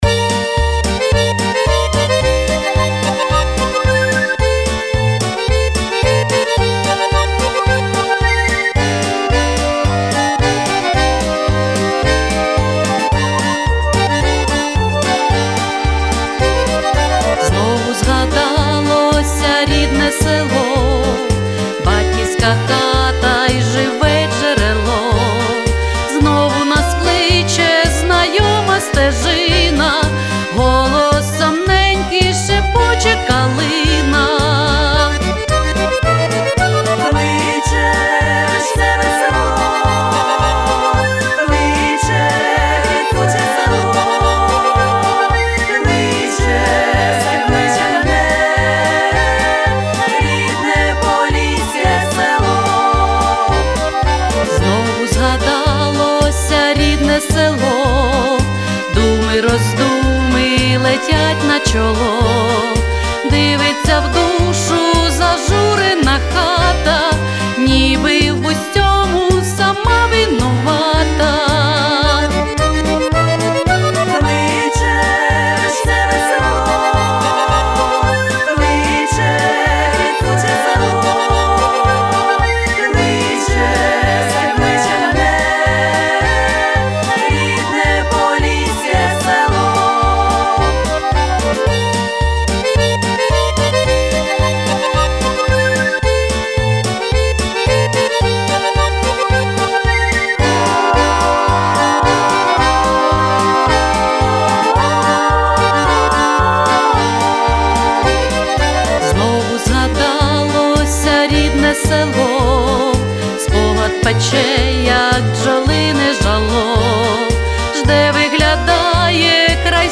Всі мінусовки жанру Folklore
Плюсовий запис